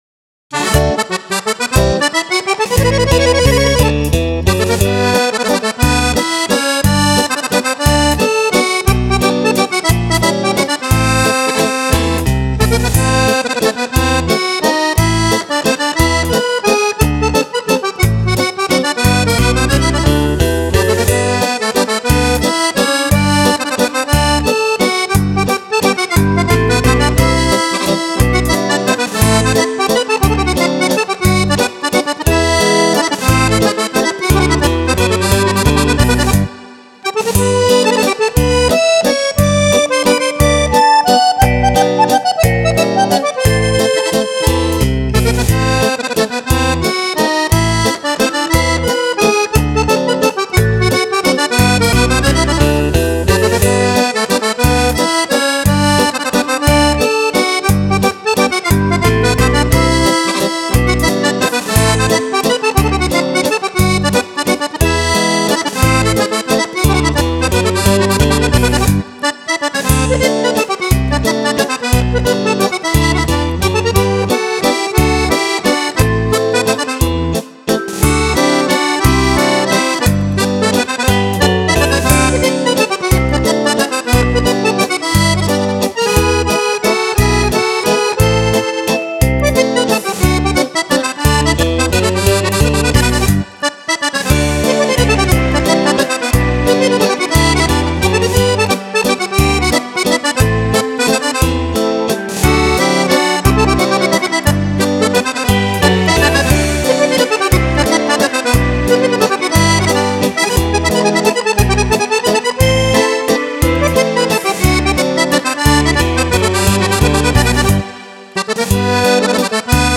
10 ballabili per Fisarmonica
Valzer